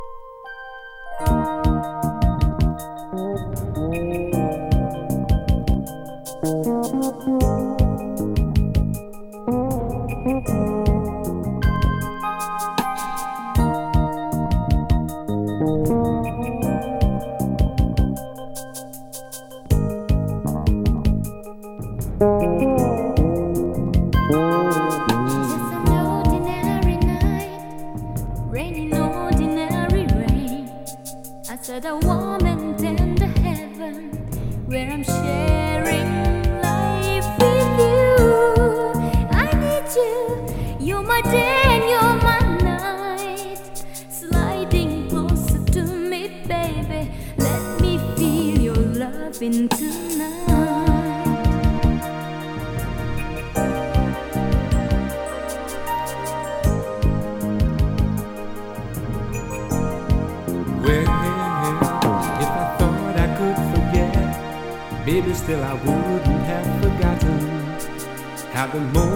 しっとりとした雰囲気の極上メロウR&Bチューン。